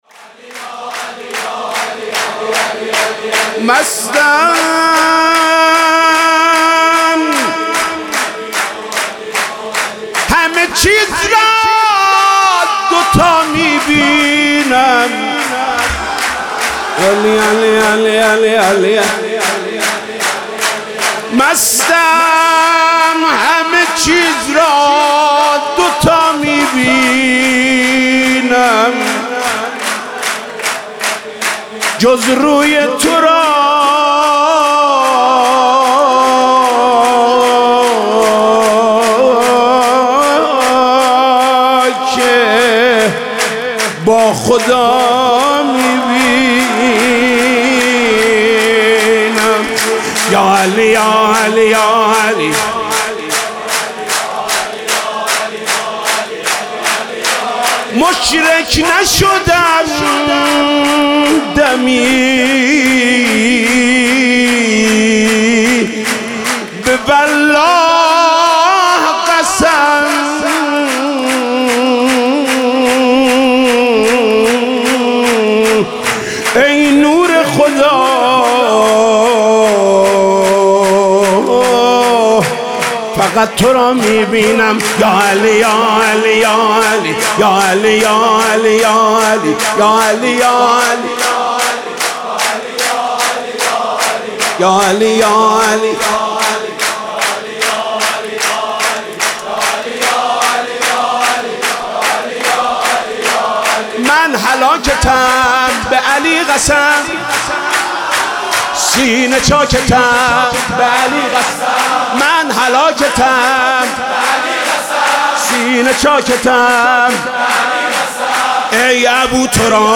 سرود: من هلاکتم به علی قسم